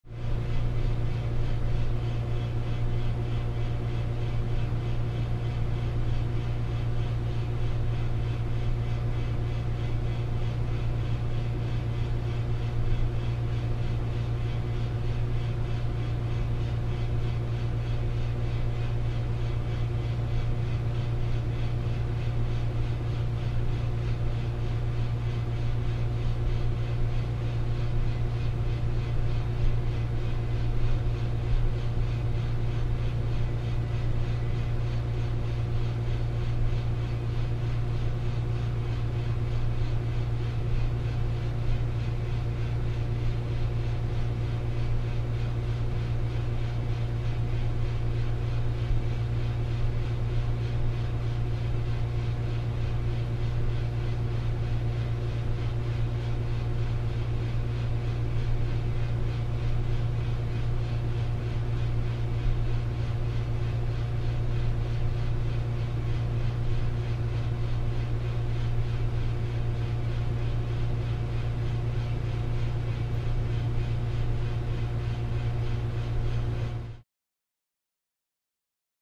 Room Ambience; Utility Room Air Conditioner Steady Pulse